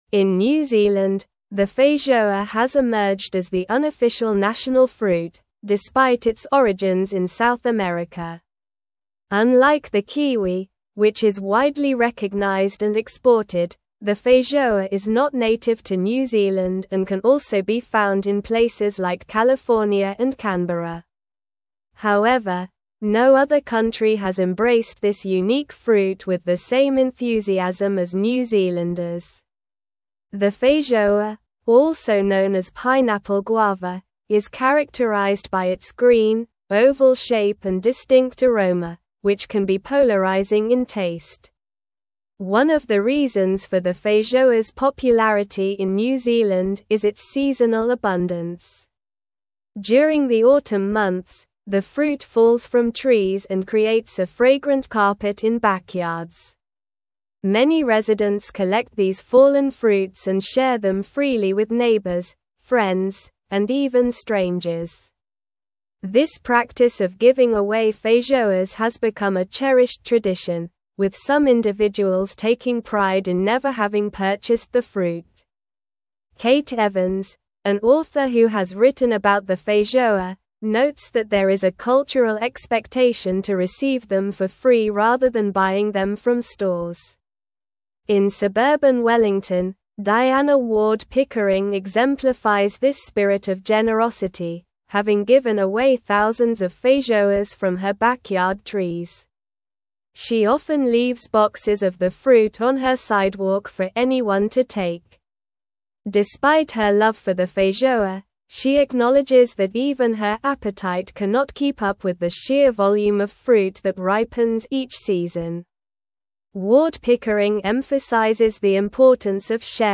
Slow
English-Newsroom-Advanced-SLOW-Reading-Feijoa-New-Zealands-Beloved-Autumn-Fruit-Tradition.mp3